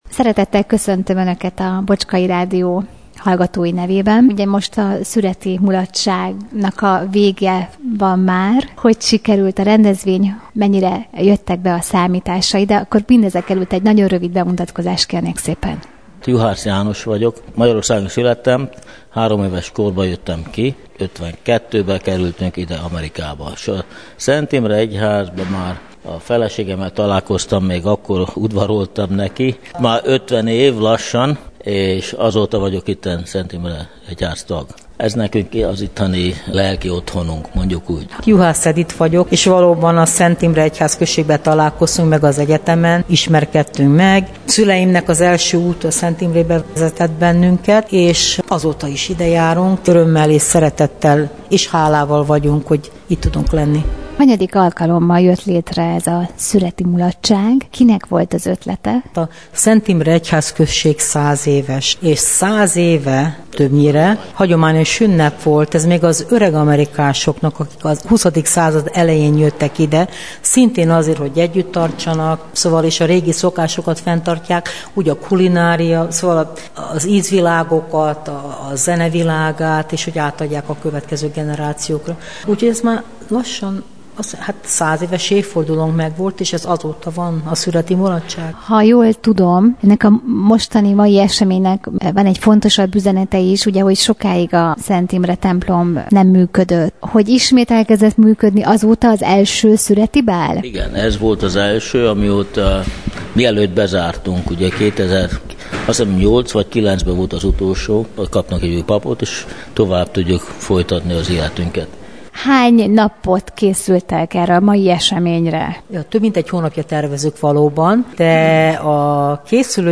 Szüreti mulatság Clevelandben – Bocskai Rádió
Hazai mulatós nótákat, dalokat játszottak, amelyekre a vendégek nagy örömmel táncoltak.